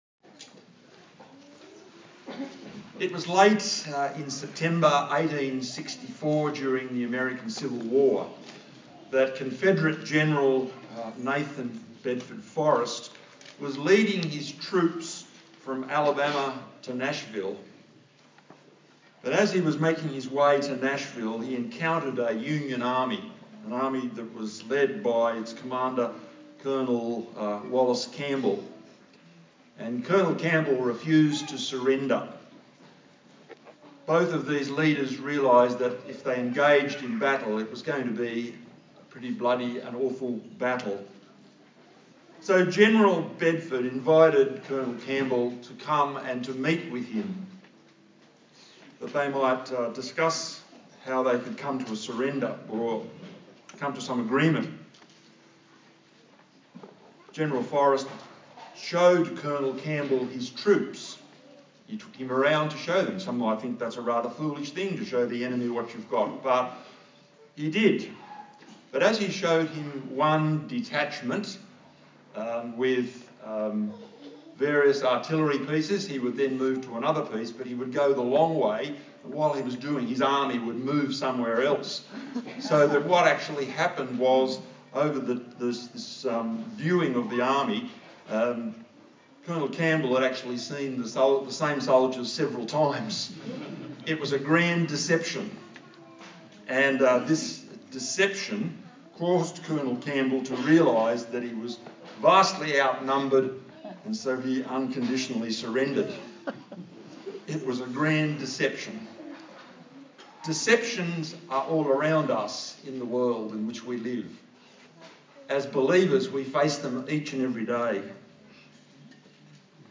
A sermon on Psalm 73
Service Type: Sunday Morning